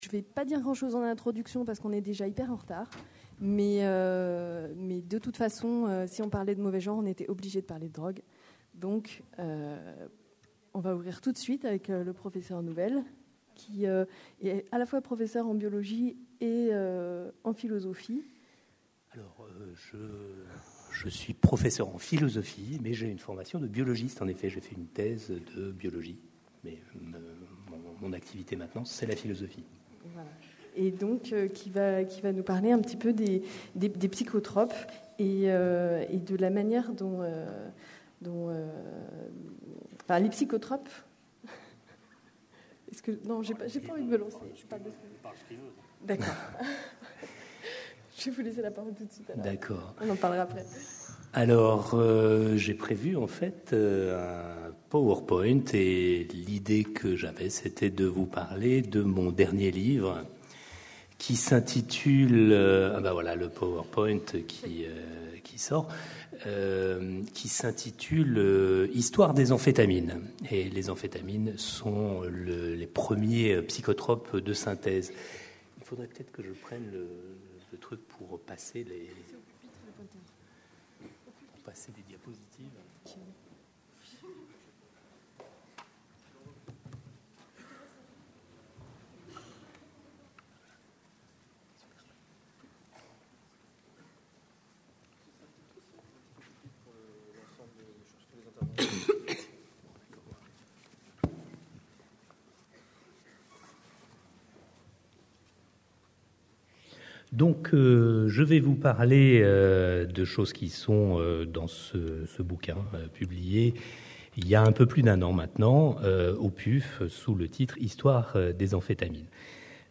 CNIPsy 2010 Marseille : 7ème Congrès National des Internes en Psychiatrie (CNIPsy).